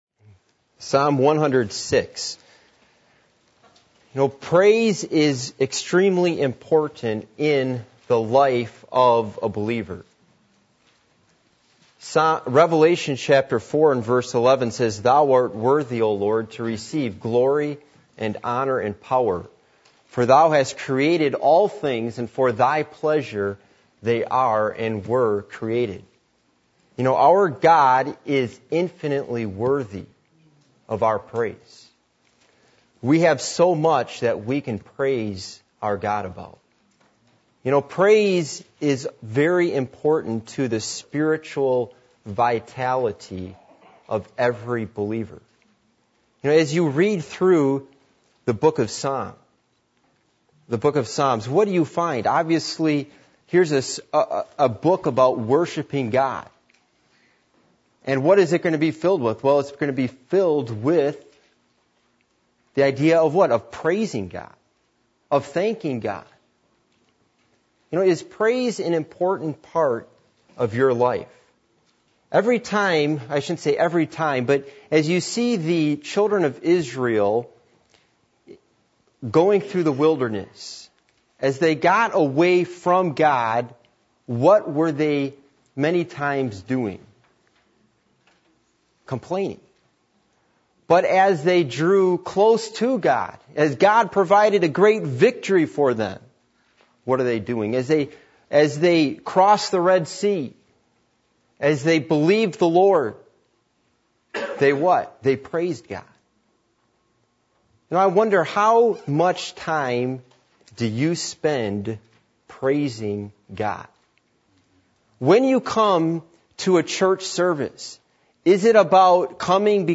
Psalm 106:1-5 Service Type: Midweek Meeting %todo_render% « The Characteristics Of False Teachers